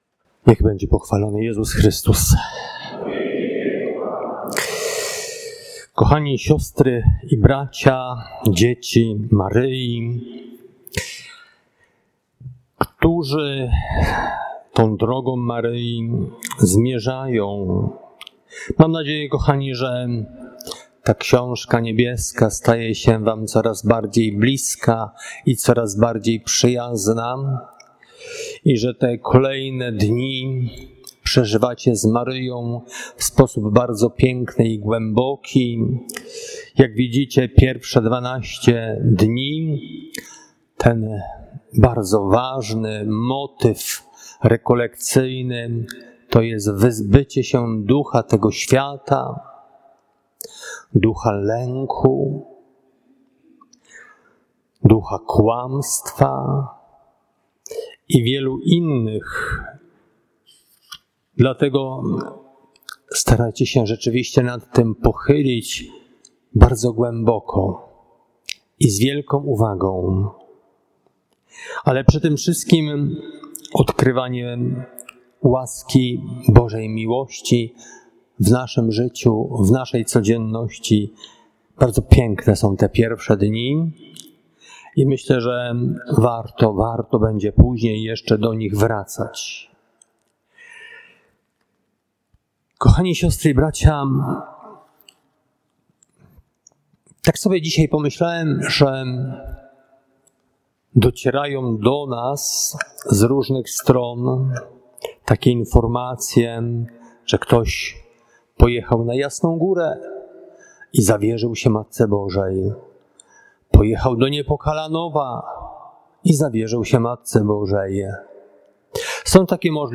Konferencja 2